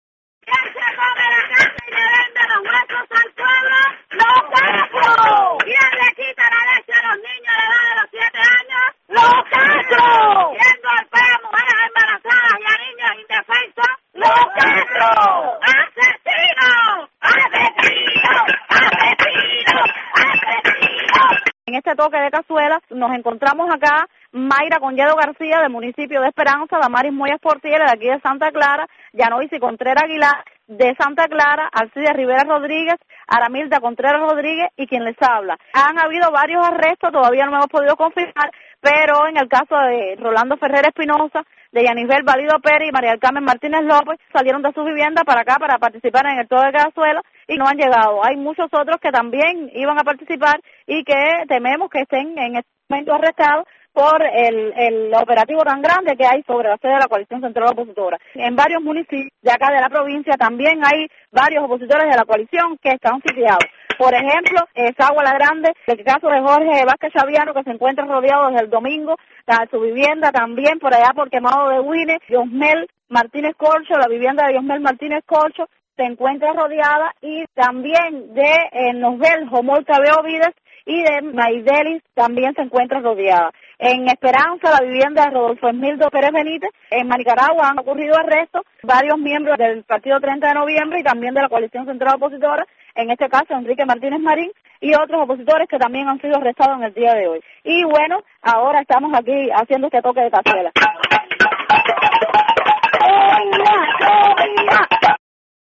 Toque de Cazuelas